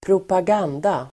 Uttal: [²propag'an:da]